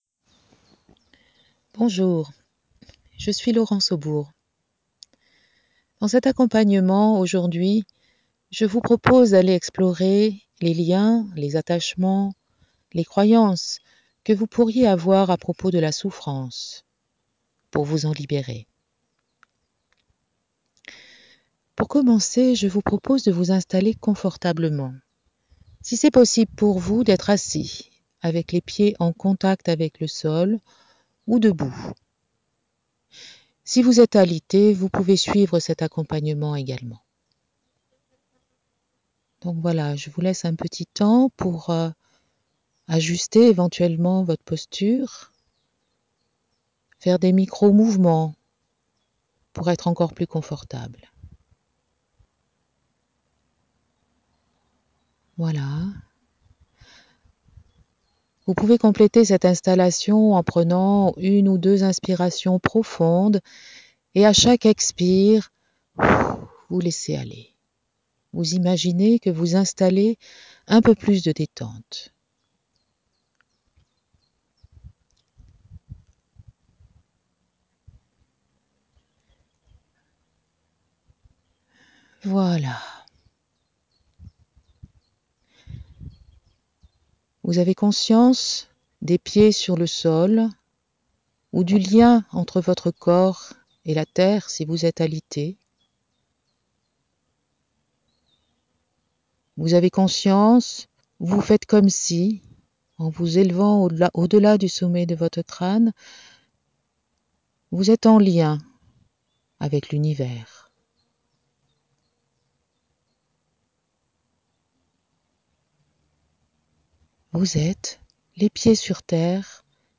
Etant enregistré en extérieur, avec mon smartphone, il n’est pas parfait, mais je vous le propose en l’état. C’était drôle d’observer que le vent se levait et venait presque couvrir mes paroles pendant les temps de libération, comme une invitation à être encore plus présent et actif.